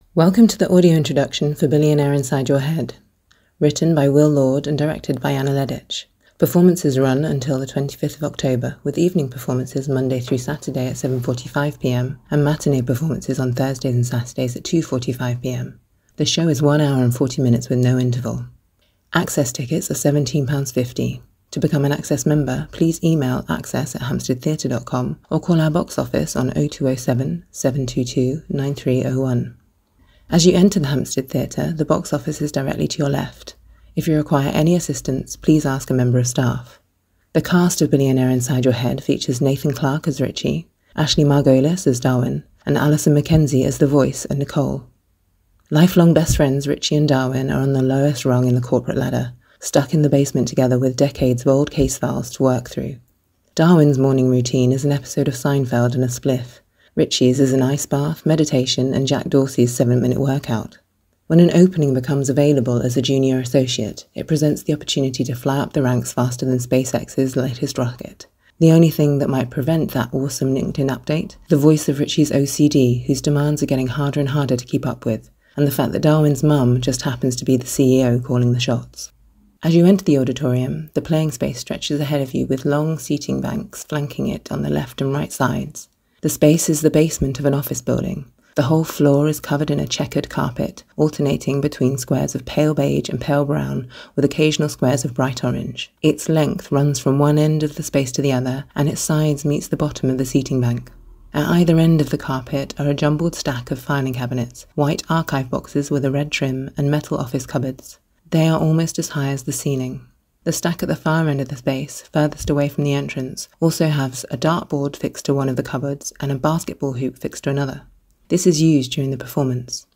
An Audio Introduction is available for this production. This is a pre-recorded introduction describing the set, characters and costumes and includes an interview with the cast.